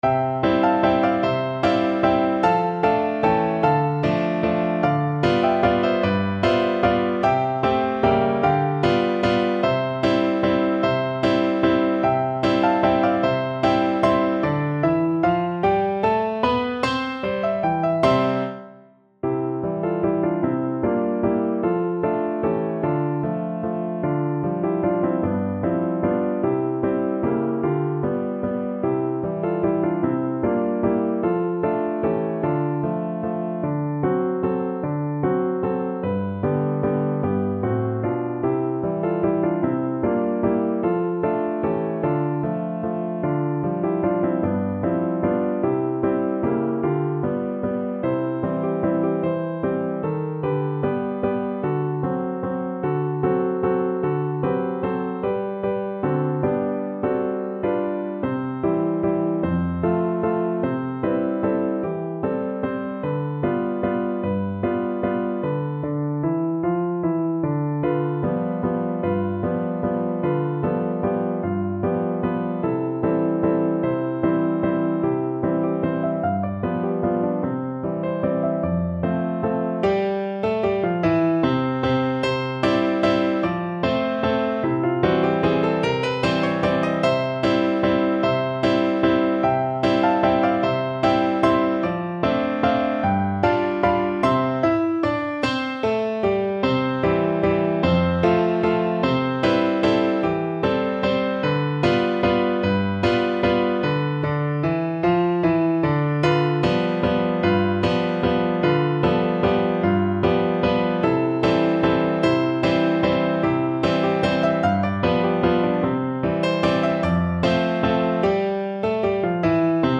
~ = 150 Tempo di Valse